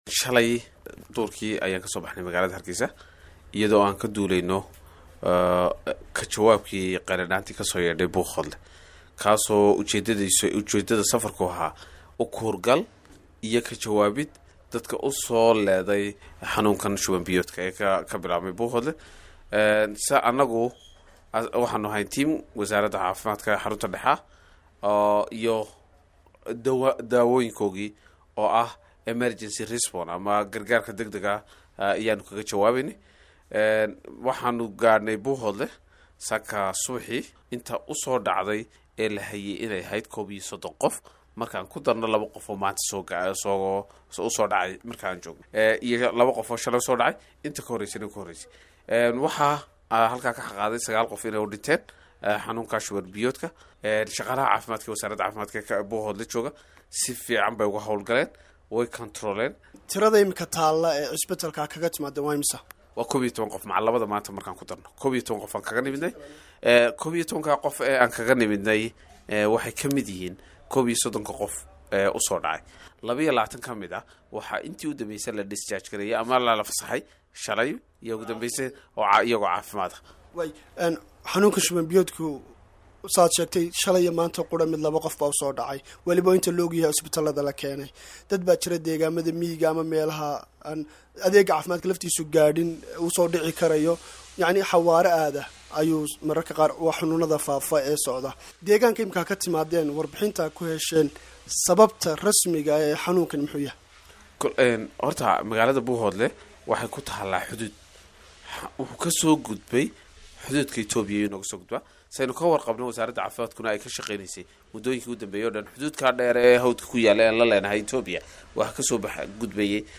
Agaasimaha ayaa u warramay weriyaha VOA